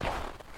footstep_snow8.mp3